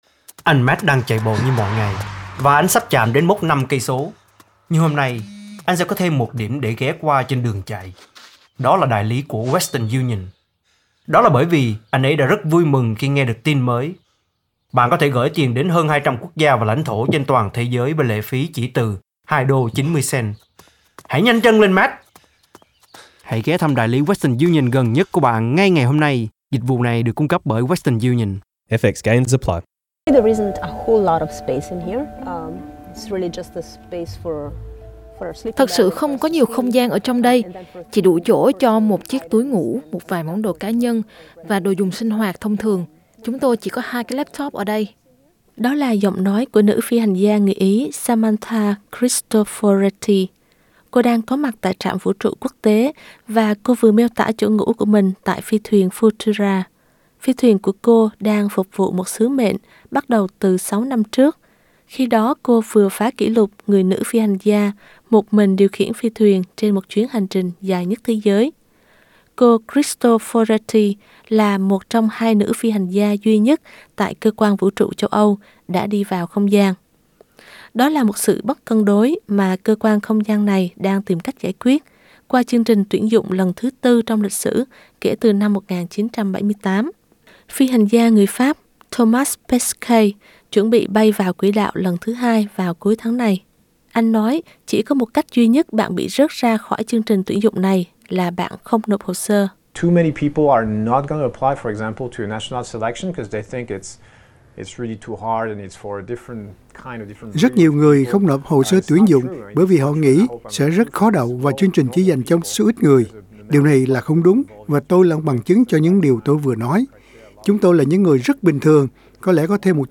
Đó là giọng nói của nữ phi hành gia người Ý Samantha Cristoforetti.